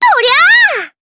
May-Vox_Attack4_jp.wav